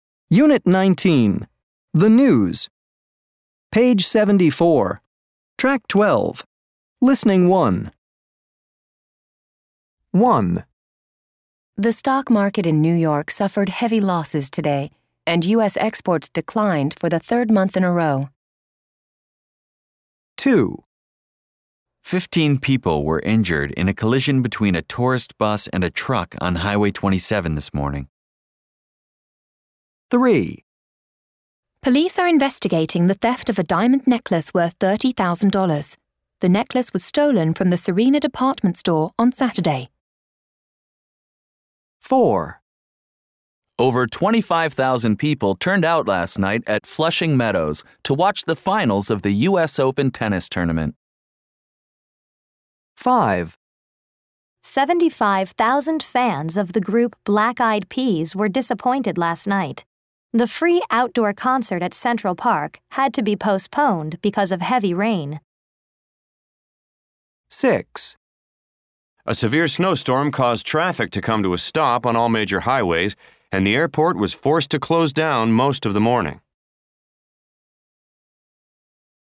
These are the beginning of news reports. what kinds of reports are they?